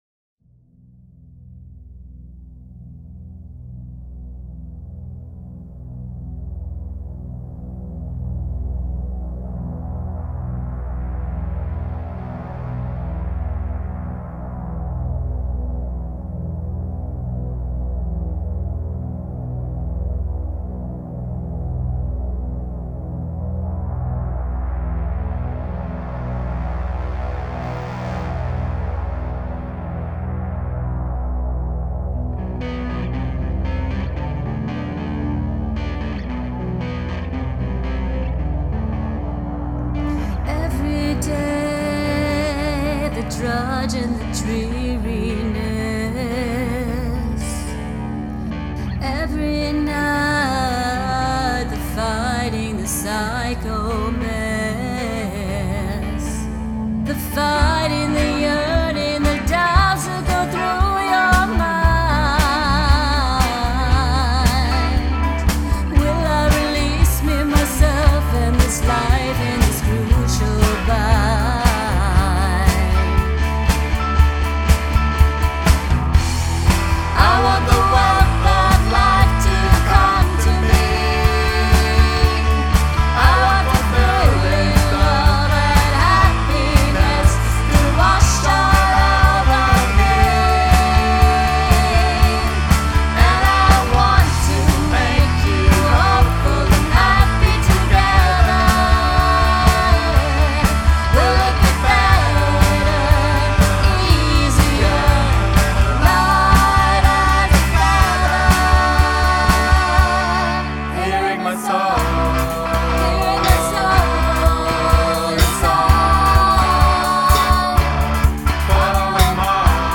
Wir sind eine Rockband aus Freiburg.
Ganz frisch aus dem Tonstudio.